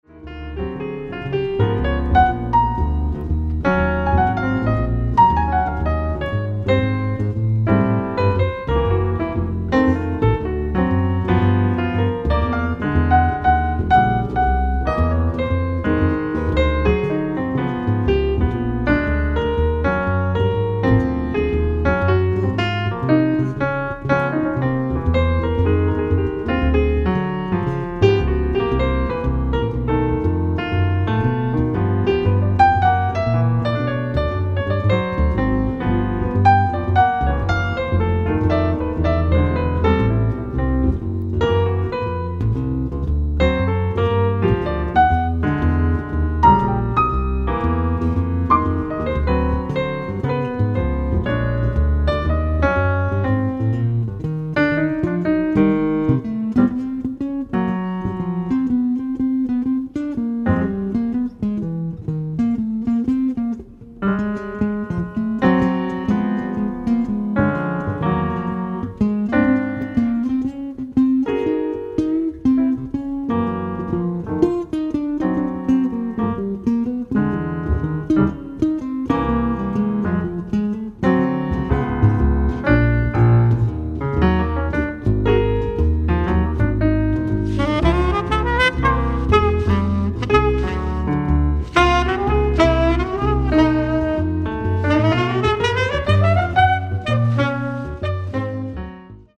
ライブ・アット・タンペレ・ジャズハプニング、タンペレ、フィンランド 10/31/2015
※試聴用に実際より音質を落としています。